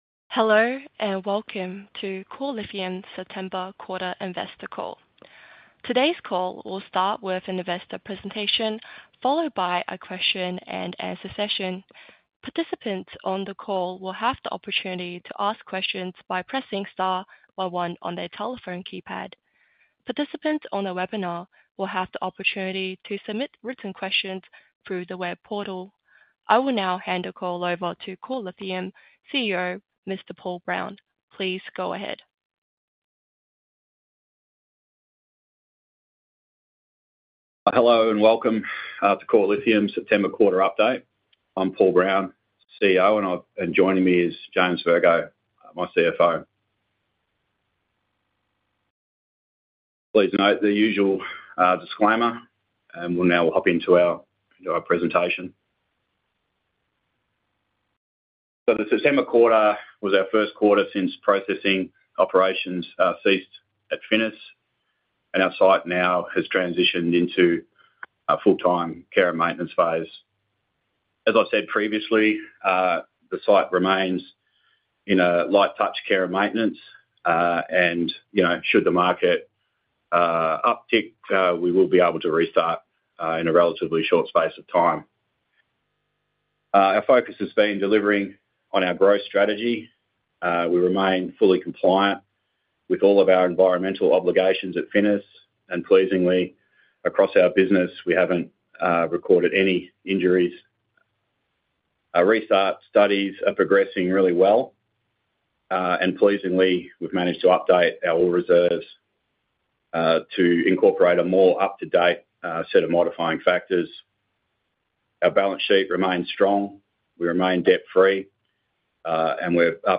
September Quarterly Investor Call Recording